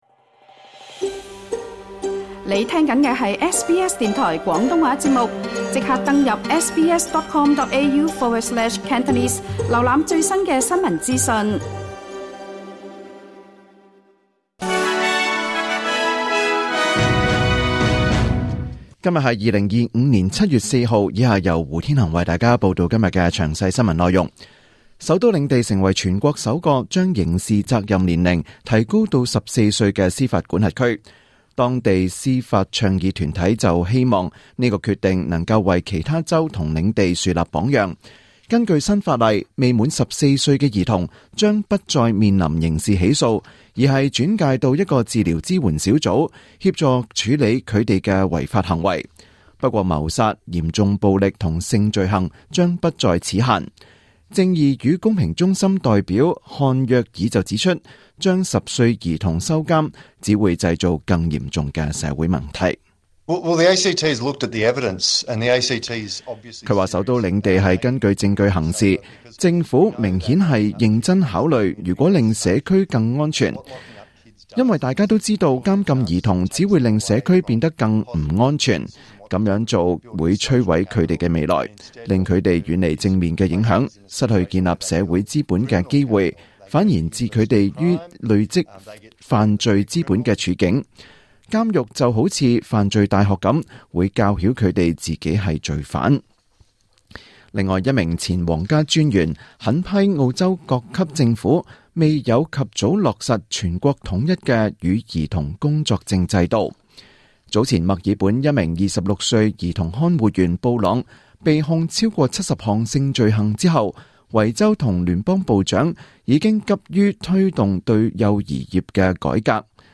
2025年7月4日SBS廣東話節目九點半新聞報道。